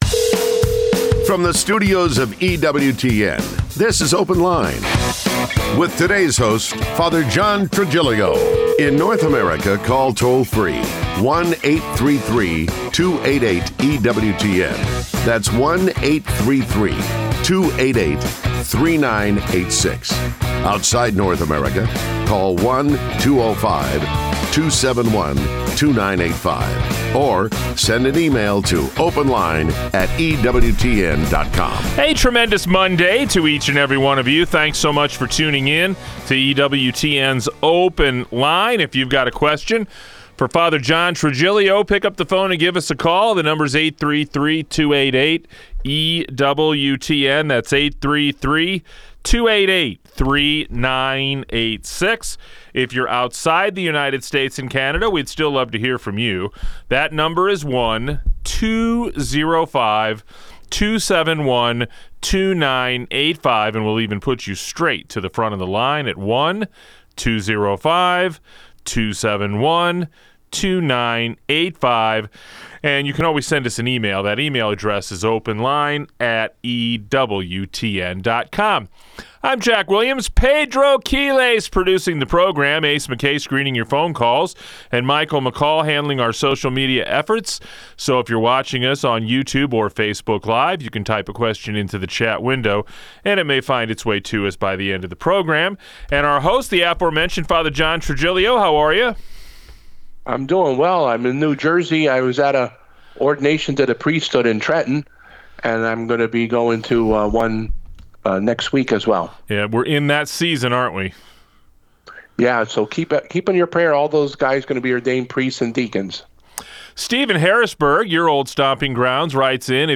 They warn against treating evil spirits as entertainment and remind listeners that spiritual warfare is real. Callers share personal experiences, ask about the reality of possession, and raise questions like whe…